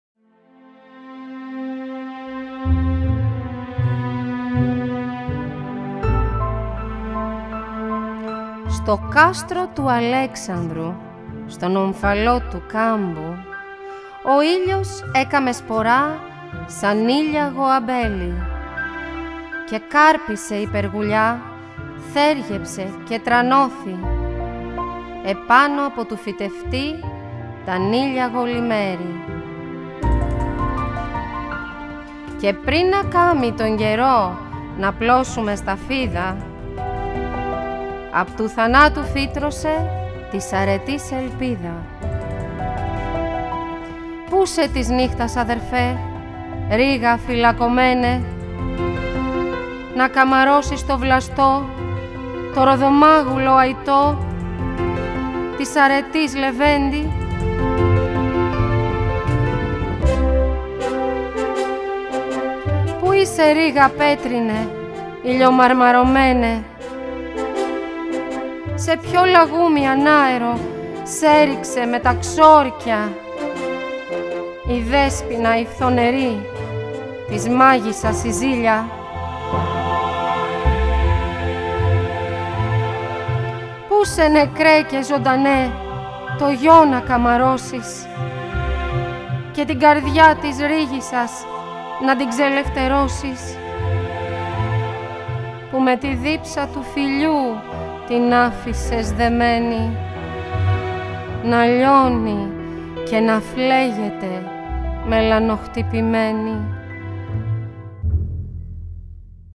Το μουσικό έργο Μελανοχτυπημένη αποτελείται απο 7 θέματα τα οποία δημιουργήθηκαν ως μουσική υπόκρουση για την απαγγελία των έμμετρων ποιημάτων του ομότιτλου μυθιστορήματος της Αρχοντούλας Αλεξανδροπούλου.
ΑΠΑΓΓΕΛΙΕΣ